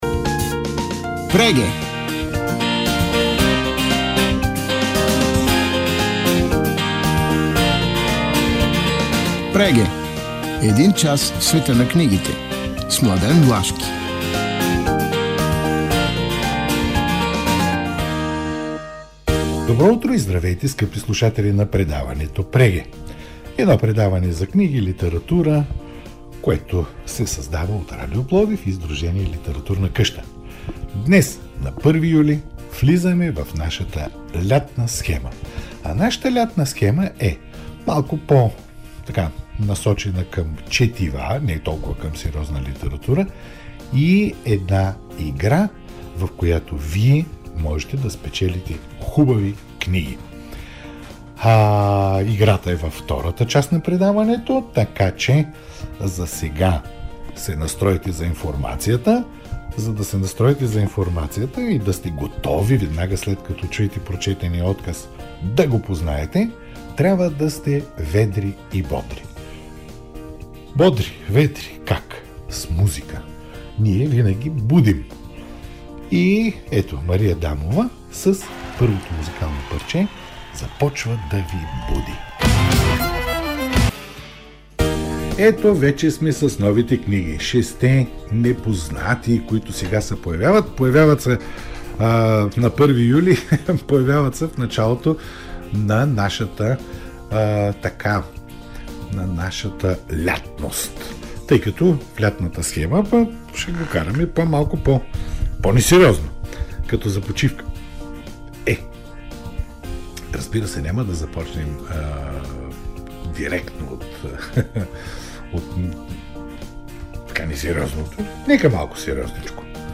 В 60 минути Нови книги и литературен календар на предаването Преге има анотации на книги, рецензии на нашумели заглавия от книжния пазар, разговори с авторитети в бранша и издатели.